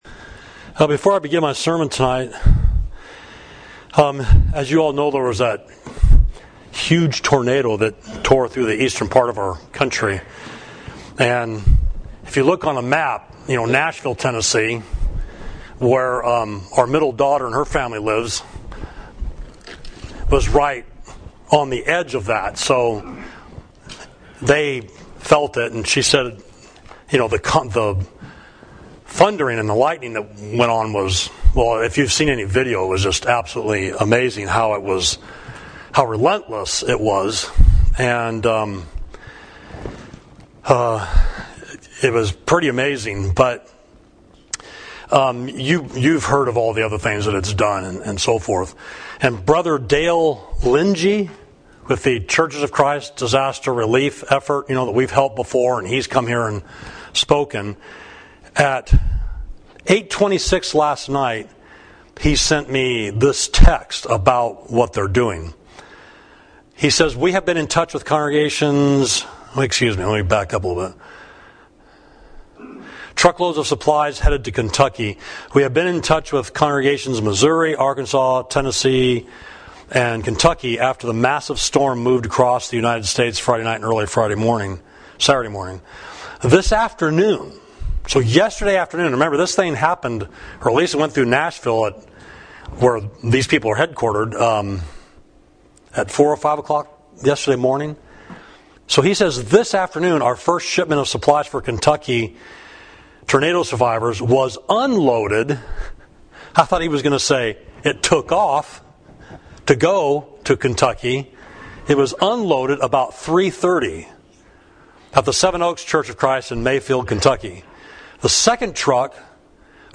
Sermon: When It Goes Well for a Nation – Savage Street Church of Christ